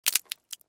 Звук отпавшей пиявки с кожи и ее падение на пол